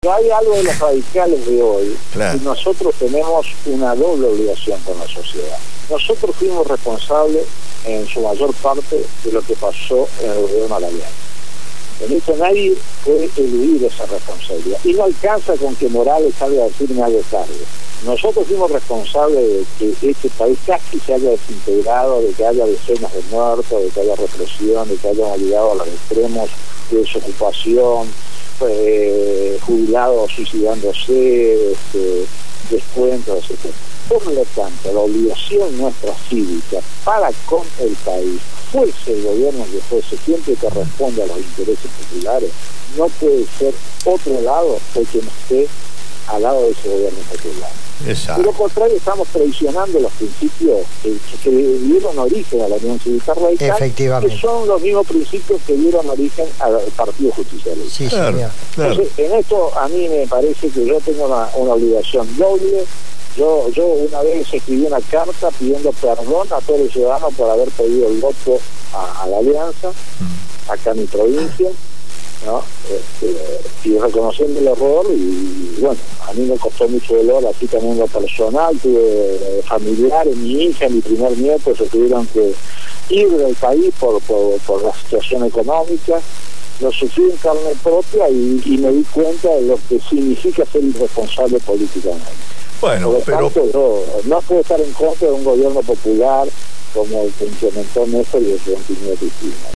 «La oposición se comporta como esos jugadores que no llegan a tiempo y en cualquier momento la sociedad les va a sacar la tarjeta roja» fueron las palabras del Senador Nacional por la provincia de Misiones Eduardo Enrique Torres en una entrevista realizada por el programa «Cambio y futuro en el aire» (Jueves de 20 a 22hs.) por Radio Gráfica.